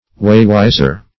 Meaning of waywiser. waywiser synonyms, pronunciation, spelling and more from Free Dictionary.
Search Result for " waywiser" : The Collaborative International Dictionary of English v.0.48: Waywiser \Way"wis`er\, n. [Cf. G. wegweiser a waymark, a guide; weg way + weisen to show, direct.]